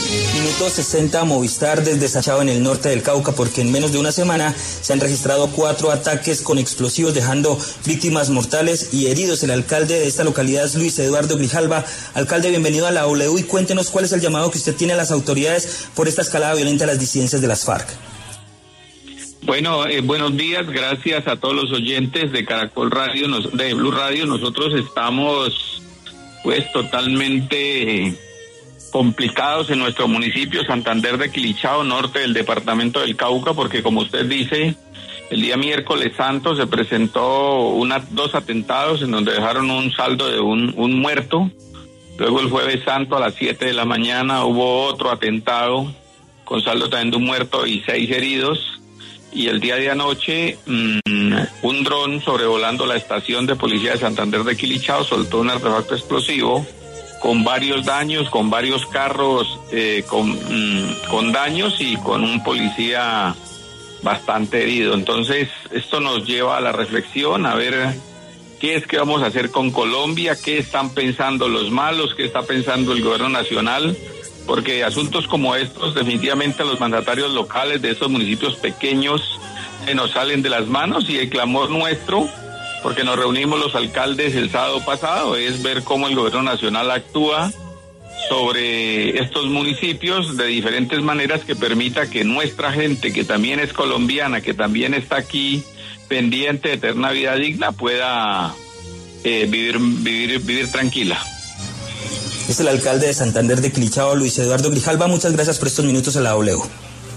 Tras la escalada terrorista de las disidencias de las Farc en Santander de Quilichao, el alcalde del municipio pasó por los micrófonos de La W para rechazar los hechos y exigir medidas al Gobierno.